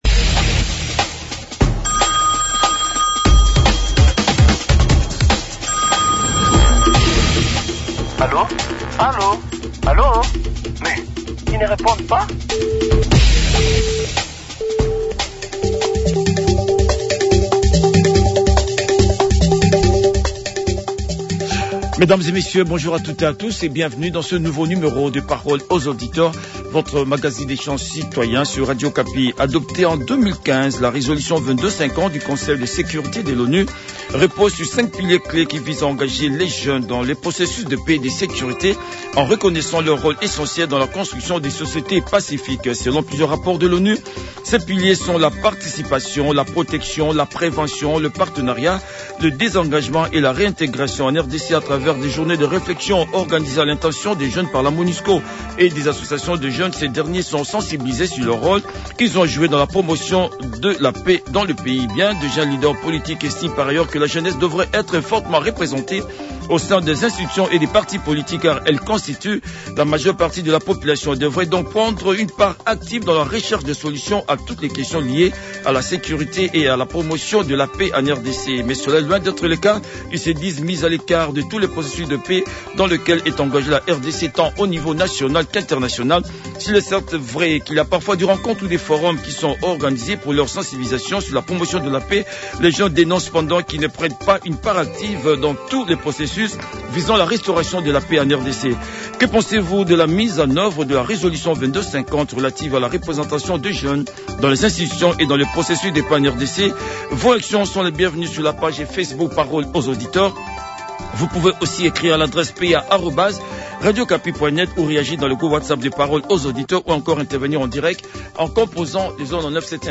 les auditeurs ont débattu